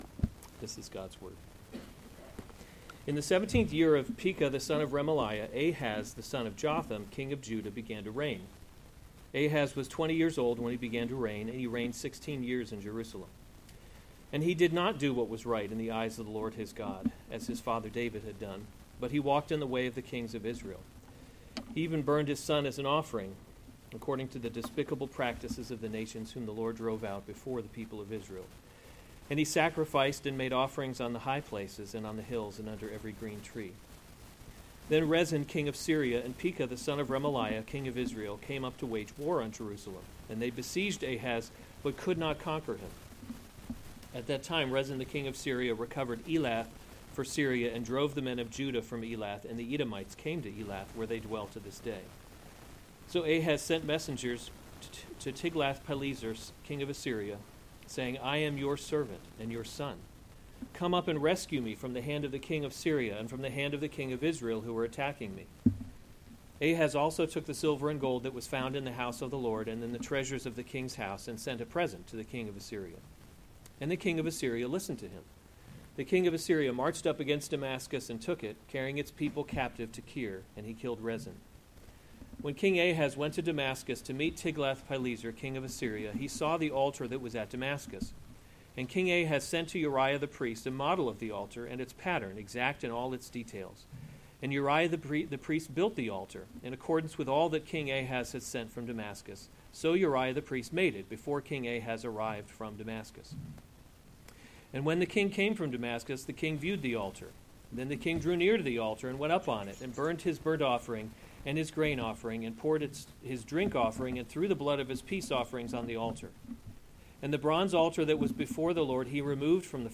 August 29, 2021 2 Kings – A Kingdom That Cannot Be Shaken series Weekly Sunday Service Save/Download this sermon 2 Kings 16:1-20 Other sermons from 2 Kings Ahaz Reigns in […]